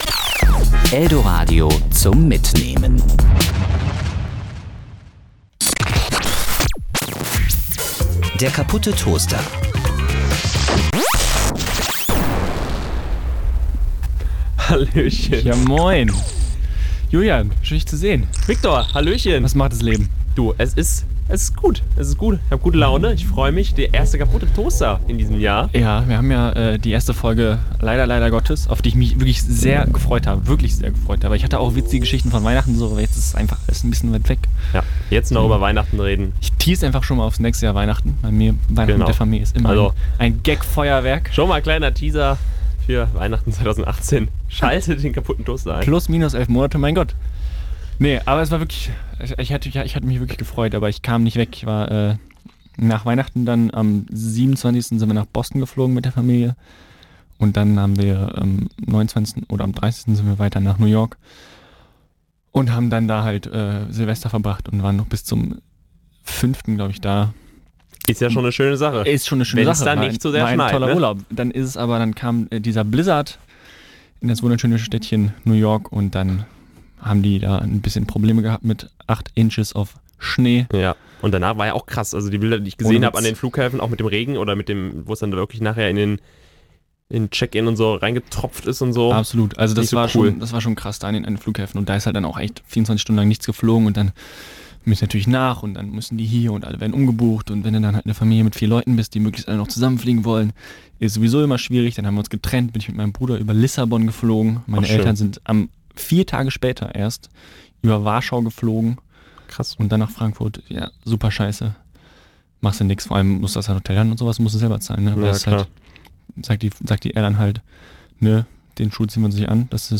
(leider ist die Sound-Quali nicht die beste, sorry for that!)